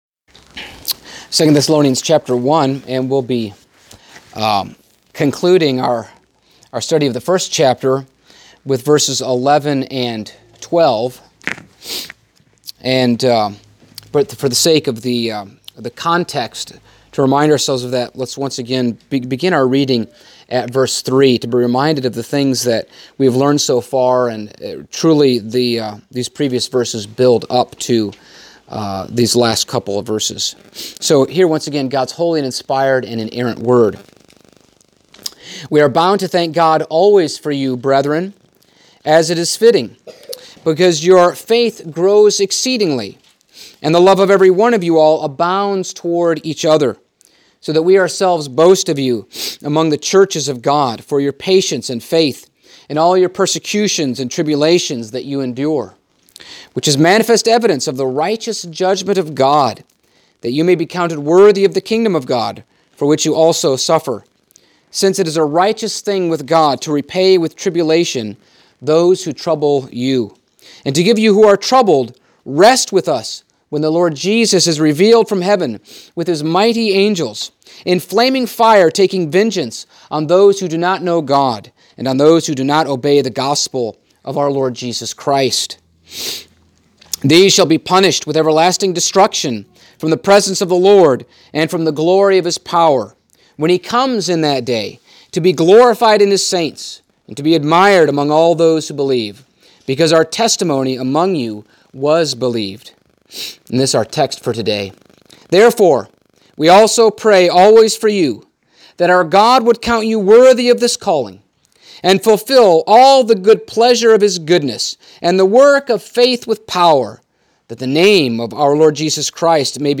Passage: 2 Thessalonians 1:11-12 Service Type: Sunday Morning